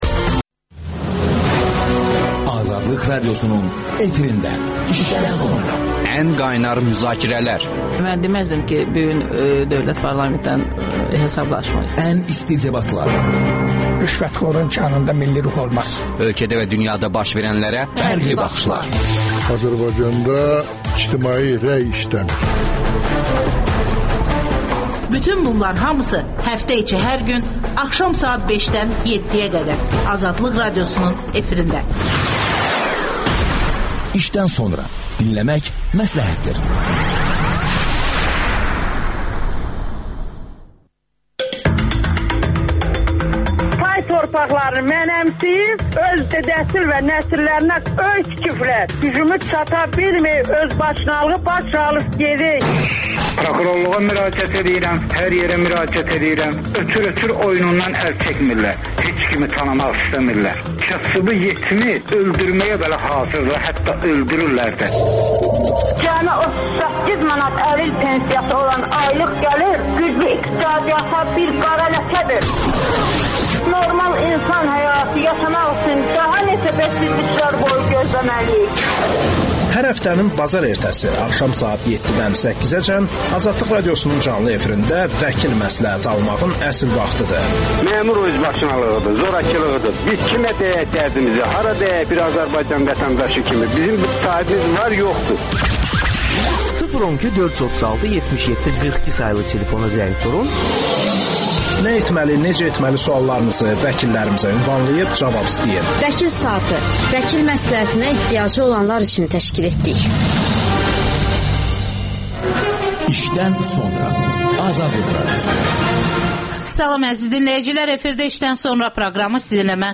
İqtisadçılar